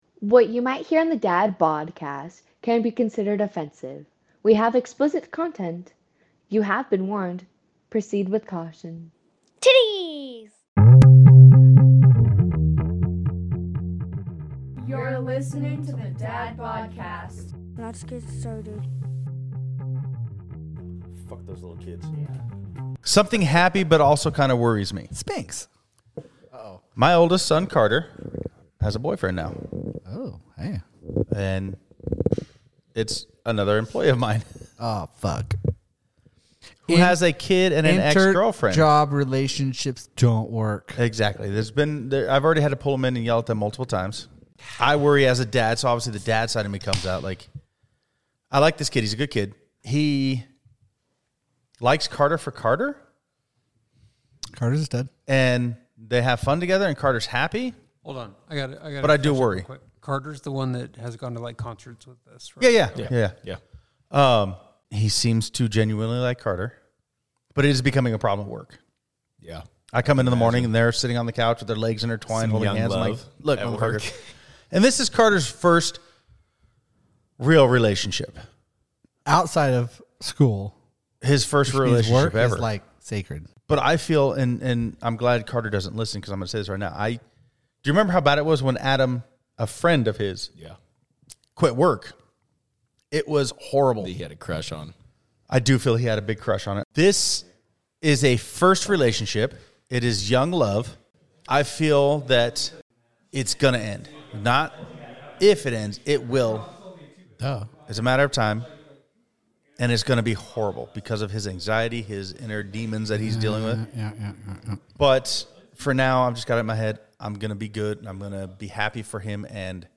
Lots of laughs, a little shit talk, tales from our childhood, and stories about raising kids today.